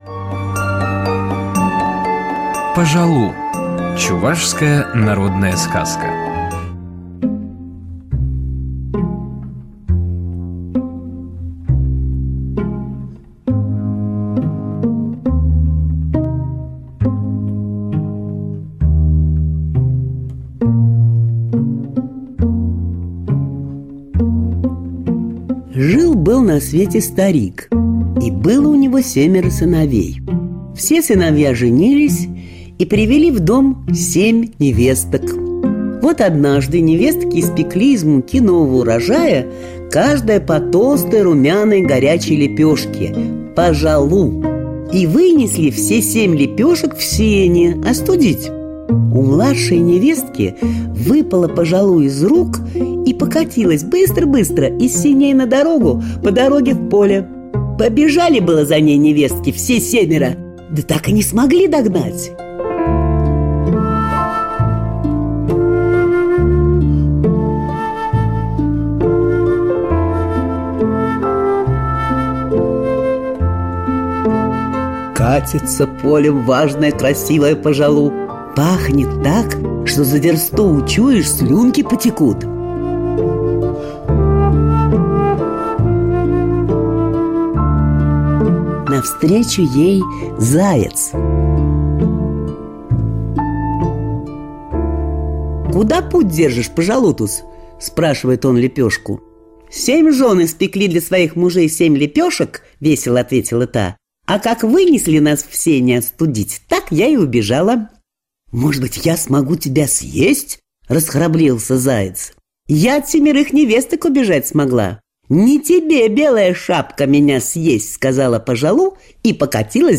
Пажалу - чувашская аудиосказка - слушать онлайн
-- / -- volume_up volume_mute audiotrack Пажалу - Чувашские Текст читает Алла Покровская. 0 421 1 1 Добавлено в плейлист (избранное) Удалено из плейлиста (избранное) Достигнут лимит